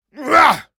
male_attack_8.ogg